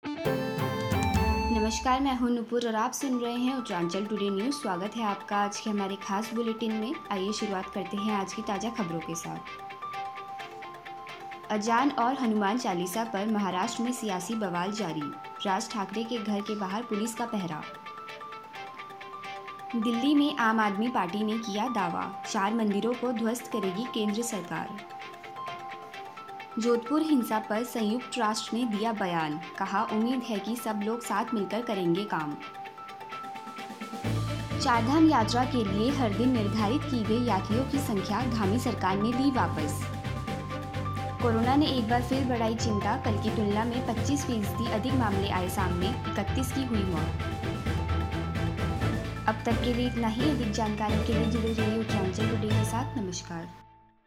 फटाफट समाचार(4-5-2022) सुनिये अब तक की कुछ खास खबरें